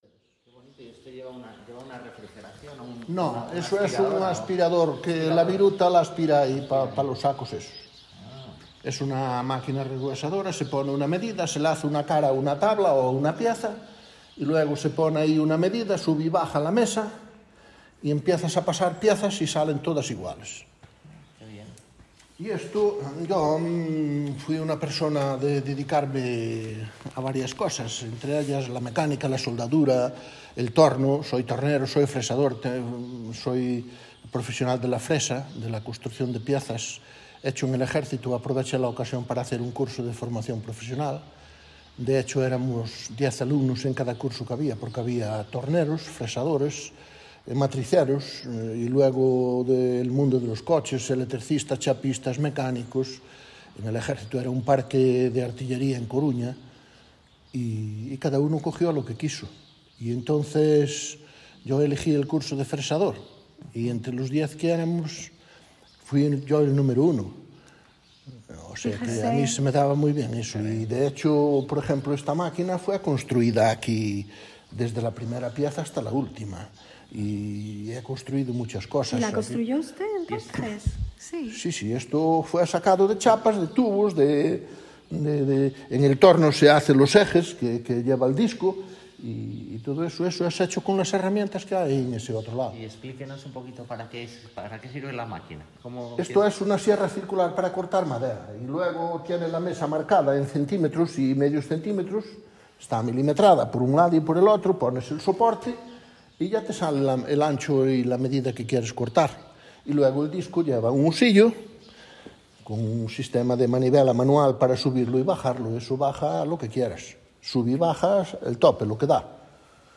Locality Brandeso (Arz�a)